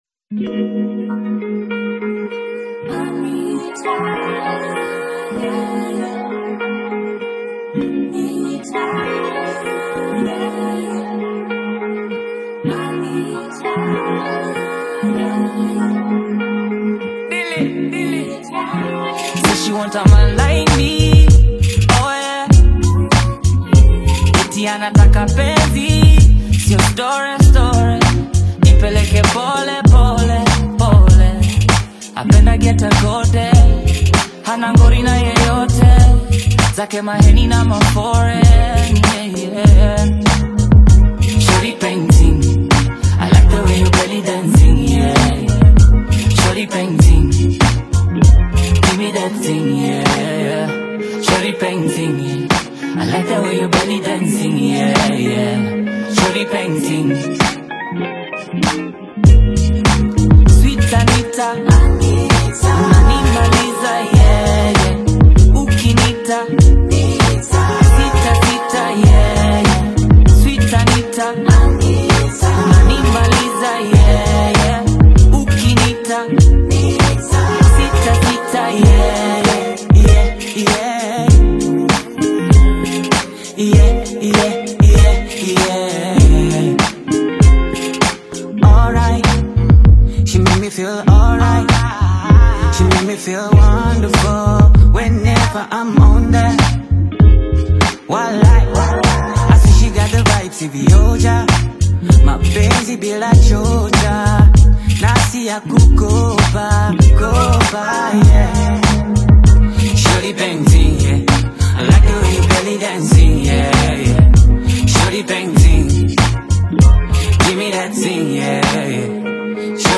Afro-Pop/Hip-Hop collaboration
smooth Afro-inspired production
soulful vocals
sharp rap delivery